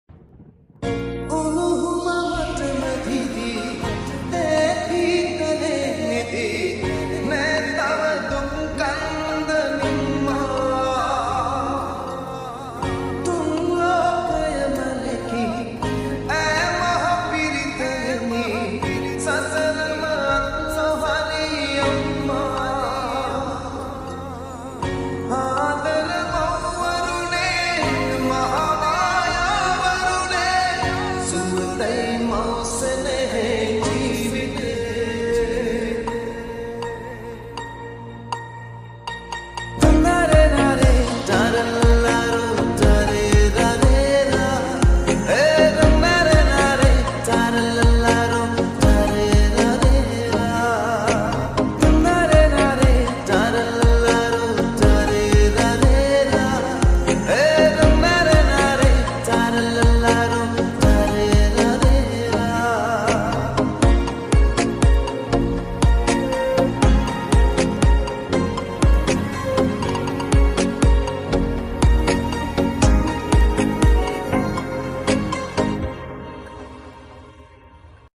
EDM Remix New Song